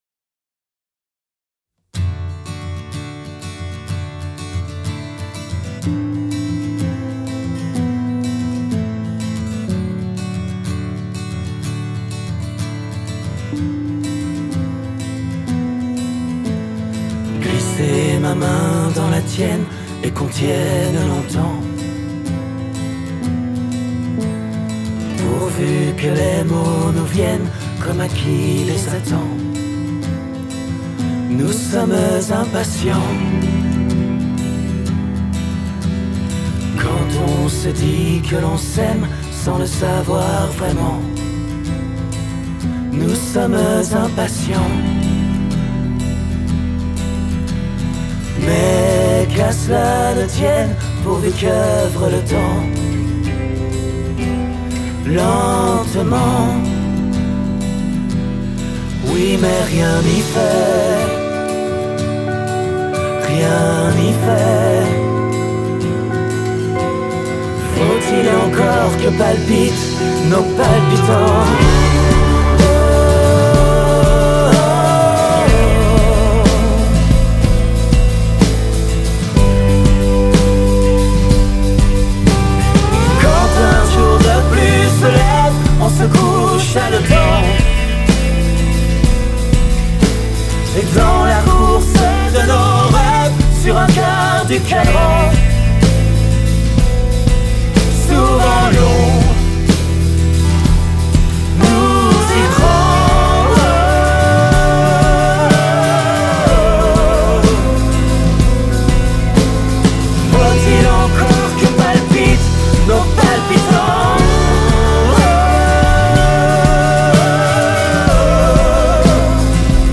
Gut, nur die Drums klingen mir zu sehr nach Metal.